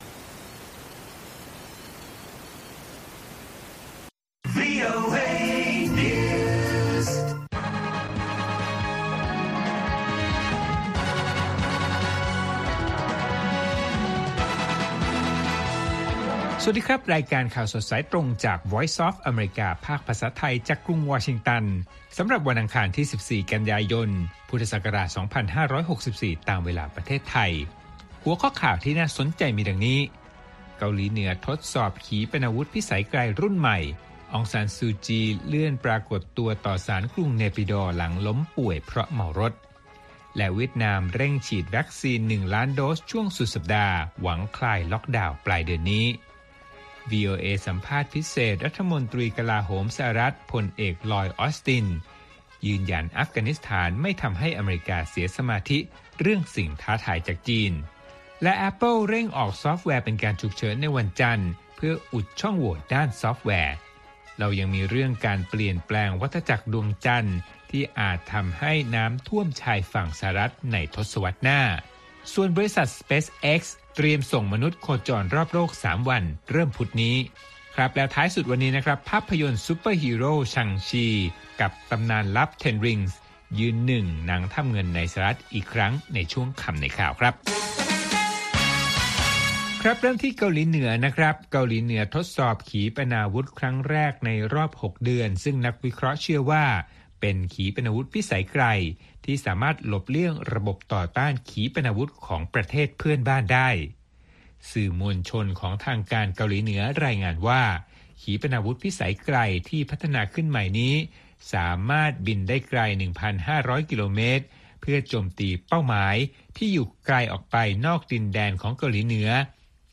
ข่าวสดสายตรงจากวีโอเอ ภาคภาษาไทย ประจำวันอังคารที่ 14 กันยายน 2564 ตามเวลาประเทศไทย